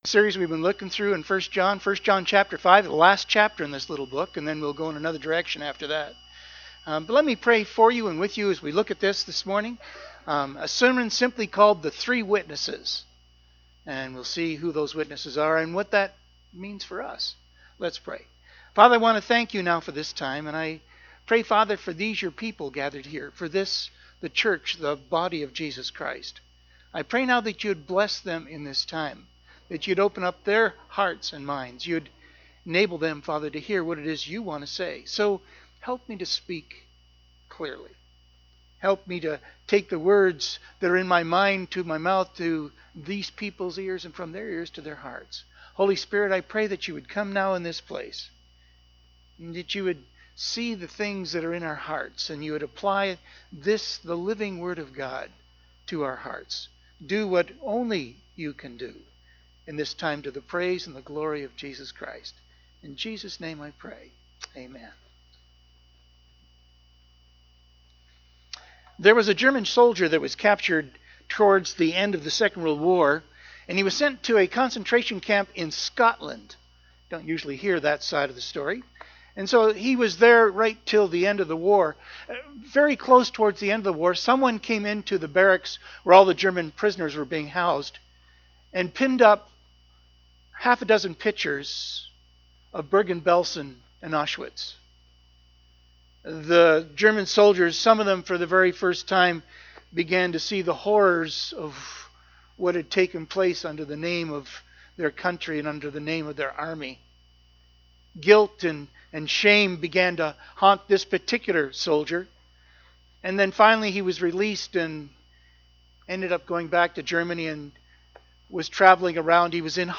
The Three Witnesses | Sermons | Resources